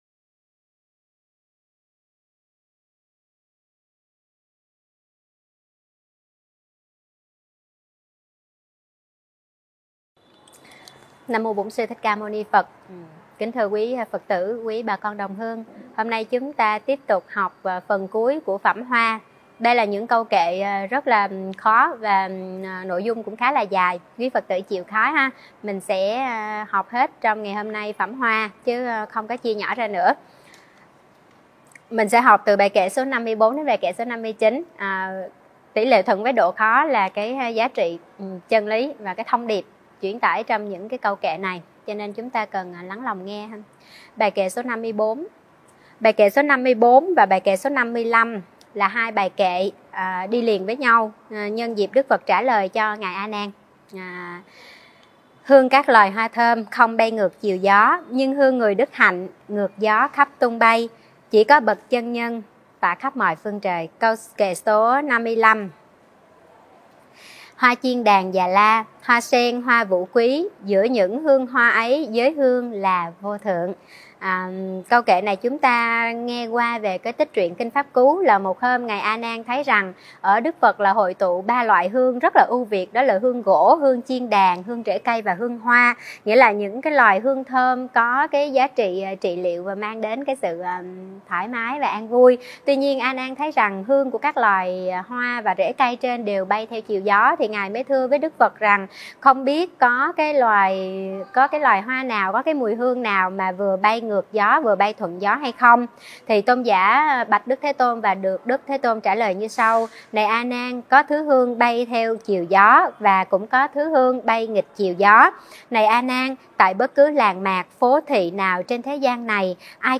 Mời quý phật tử nghe và tải mp3 thuyết pháp Hương người đức hạnh - Kinh Pháp Cú 11